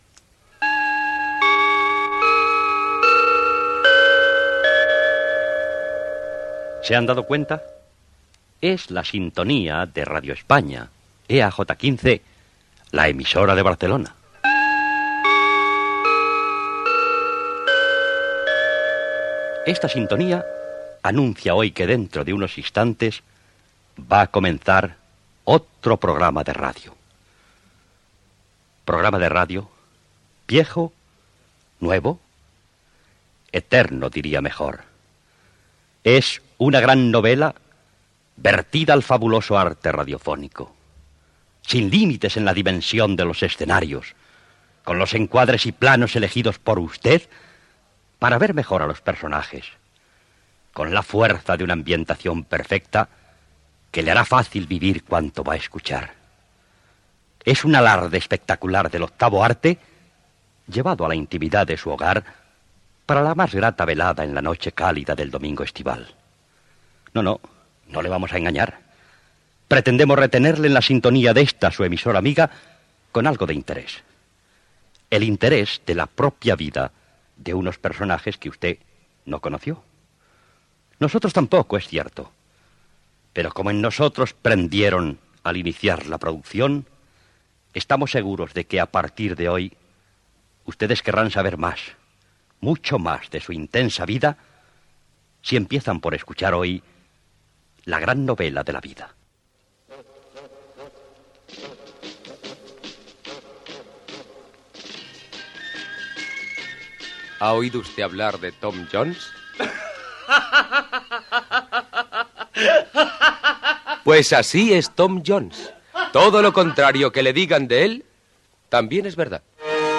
Adaptació de l'obra "Tom Jones" d'Henry Fielding. Presentació del programa, careta amb el repartiment.
Ficció